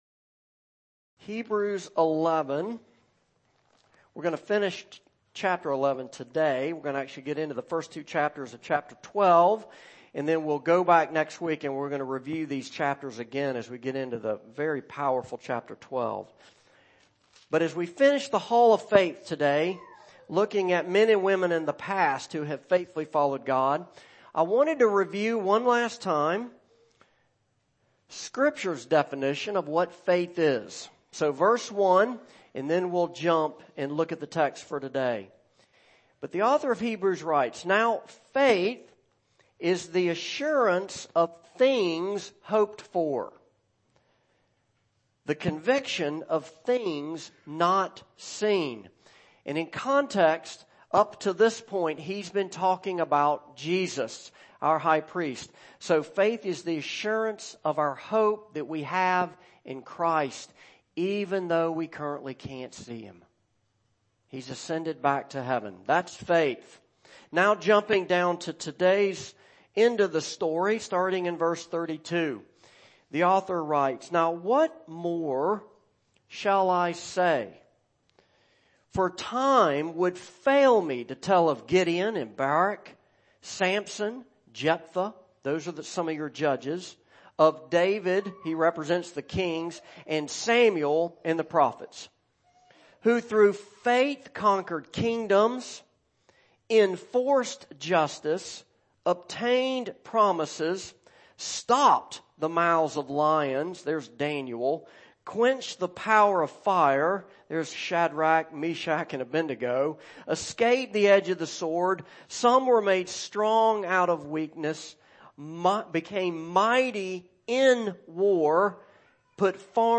Passage: Hebrews 11:32-40, 12:1-3 Service Type: Morning Service